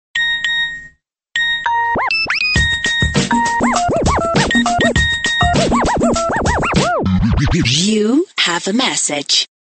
Nokia Sms RingTones